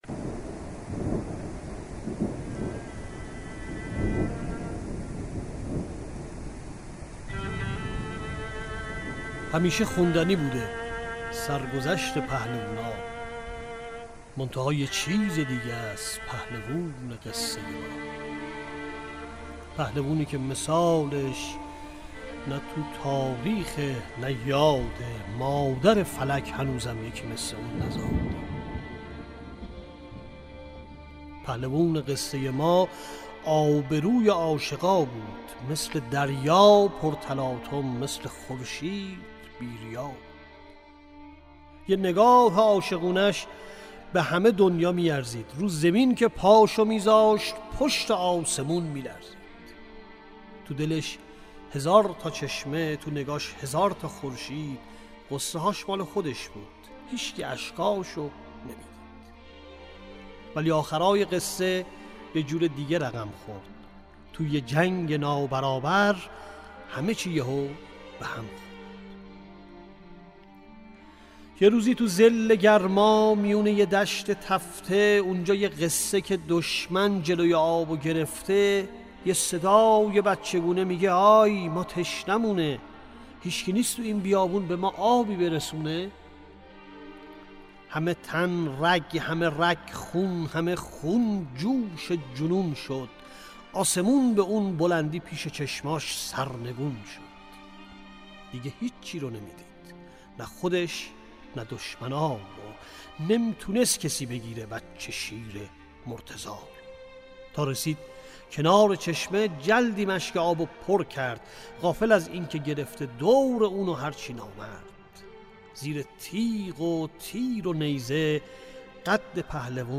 شعرخوانی
با موسیقی از حسین علیزاده